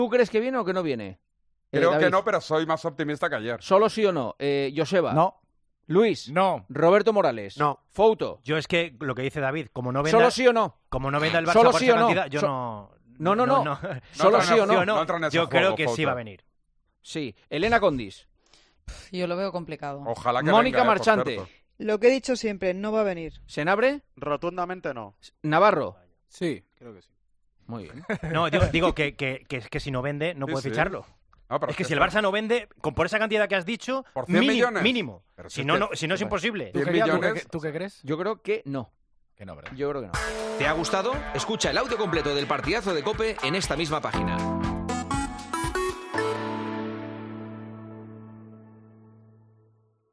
El presentador de 'El Partidazo de COPE' expone su pensamiento sobre el posible regreso del jugador